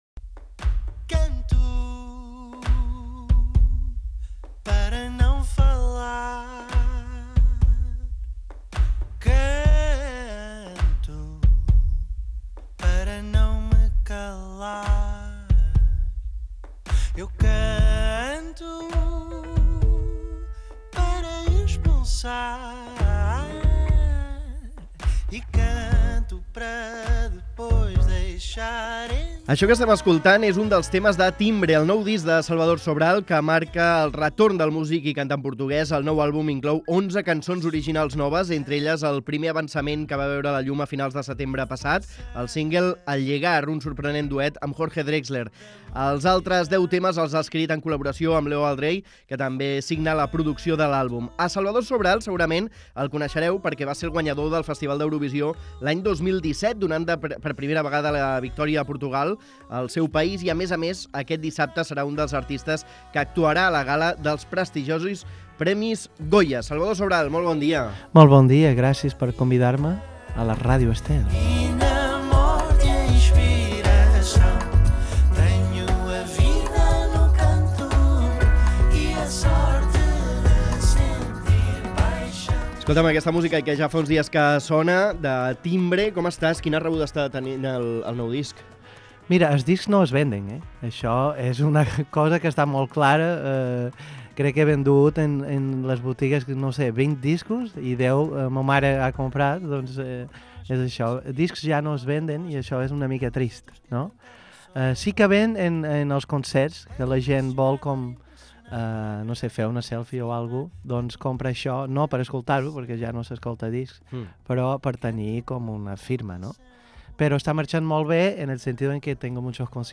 El músic Salvador Sobral als estudis de Ràdio Estel al febrer de 2024 Salvador Sobral sobre Eurovisió El músic portuguès Salvador Sobral advoca per la pluralitat lingüística d'Espanya a l'hora de competir a Eurovisió.